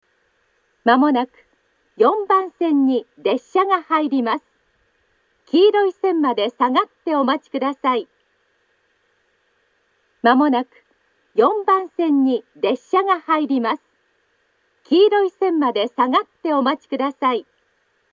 発車メロディーと接近放送があります。
４番線接近放送
aizu-wakamatsu-4bannsenn-sekkinn.mp3